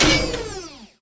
WpnLaserBlueHReleaseB.wav